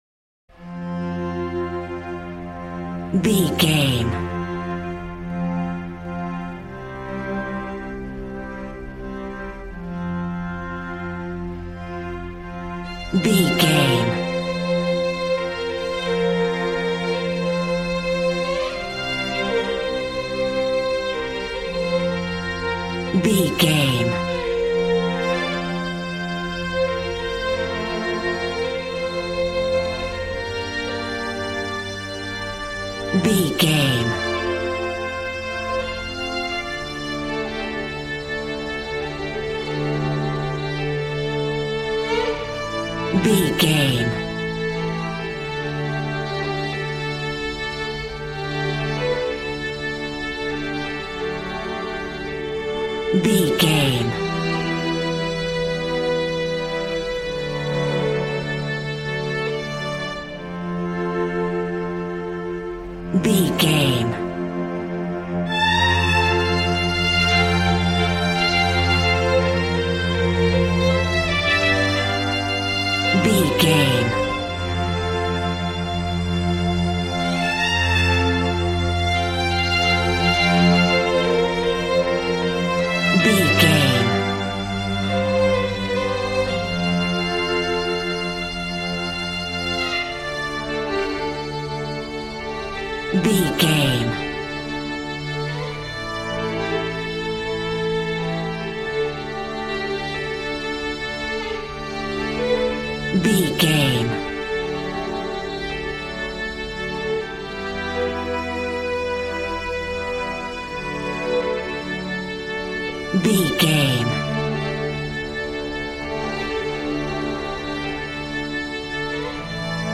Ionian/Major
regal
brass